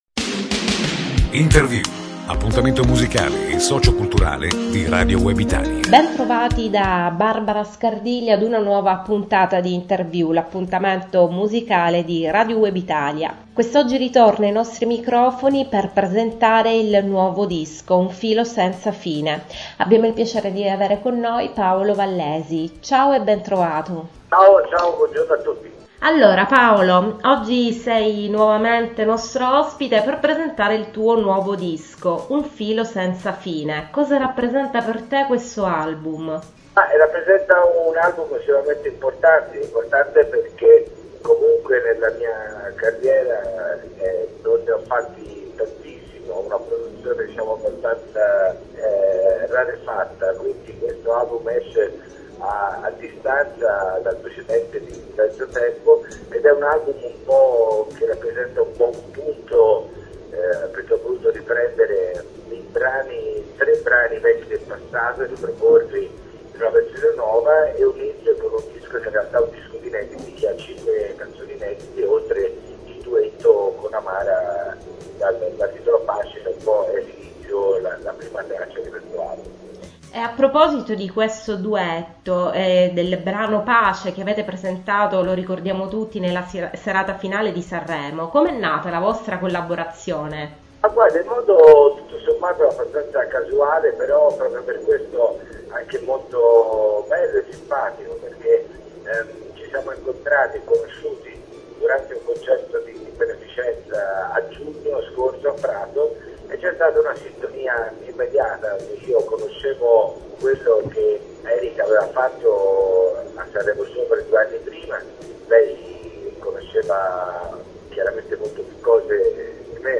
Martedì 14 marzo alle 18.00, Paolo Vallesi sarà ospite dei microfoni di “Interview” – appuntamento musicale di Radio Web Italia – per parlarci del nuovo album “Un Filo Senza Fine”, disponibile in tutti gli store e i negozi di dischi.